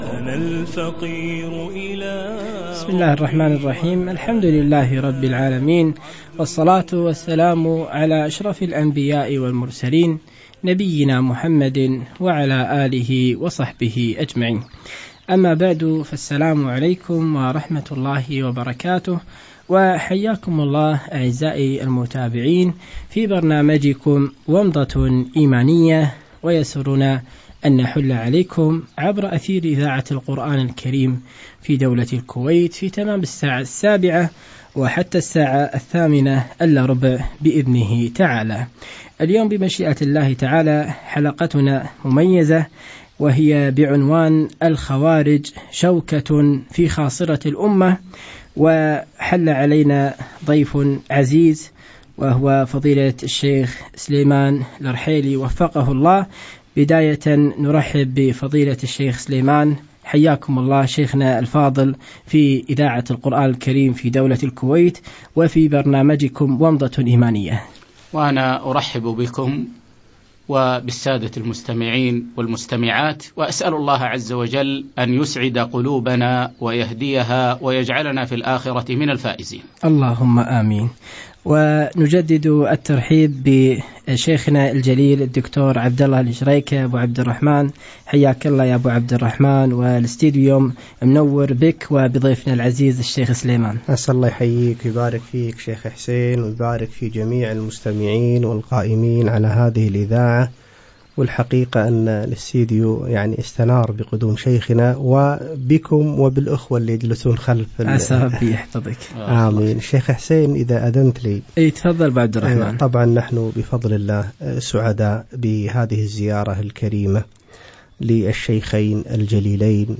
ومضة إيمانية برنامج عبر إذاعة القرآن الكريم بدولة الكويت يوم الجمعة 18 شعبان 1436 الموافق 5 6 2015
الخوارج شوكة في خاصرة الأمة - لقاء إذاعي